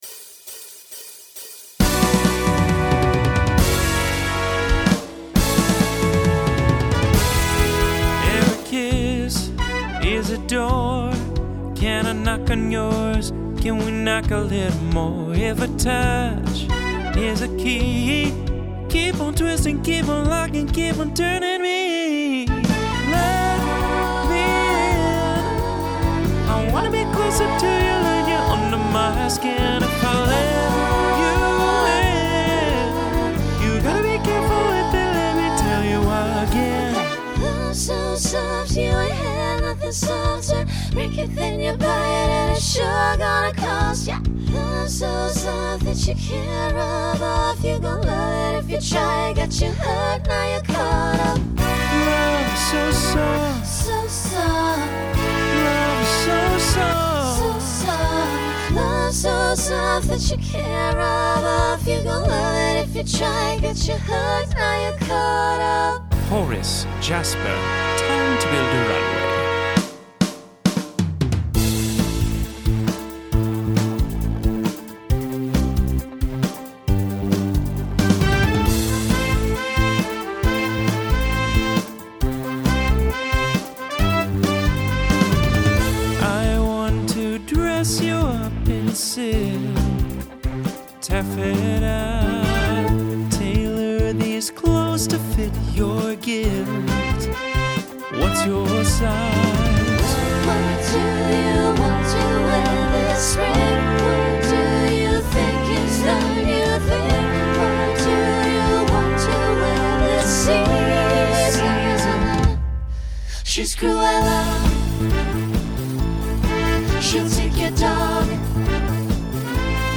Genre Pop/Dance Instrumental combo
Costume Change Voicing Mixed